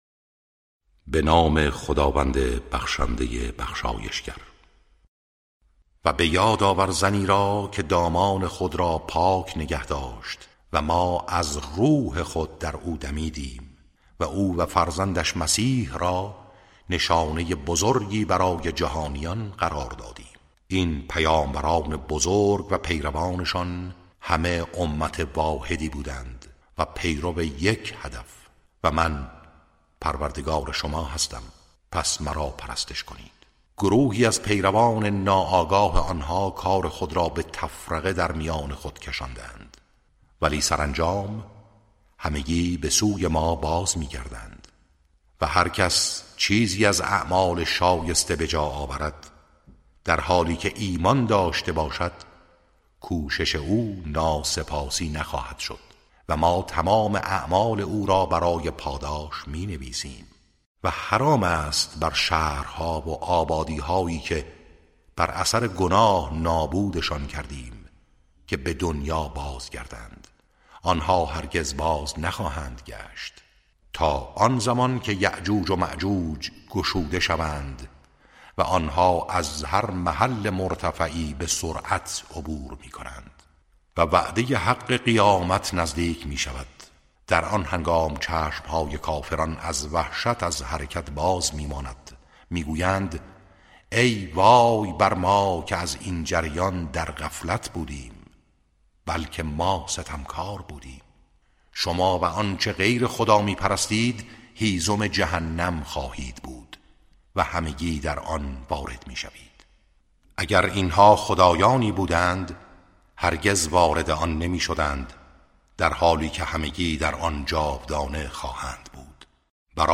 ترتیل صفحه ۳۳۰ سوره مبارکه انبیاء(جزء هفدهم)